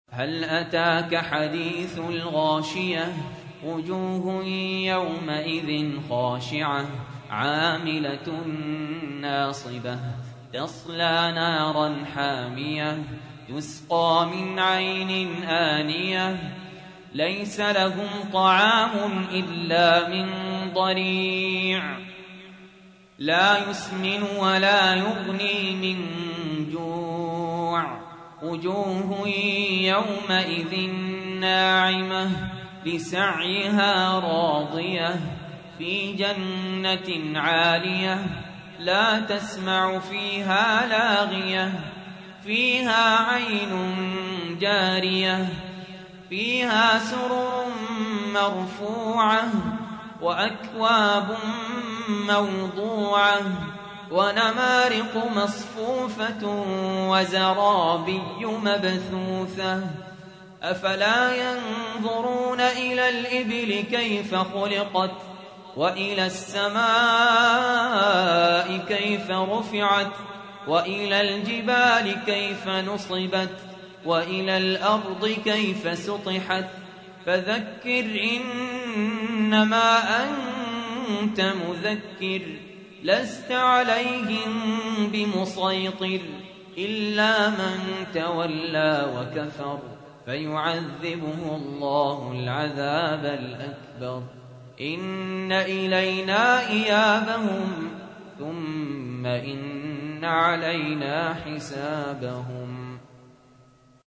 تحميل : 88. سورة الغاشية / القارئ سهل ياسين / القرآن الكريم / موقع يا حسين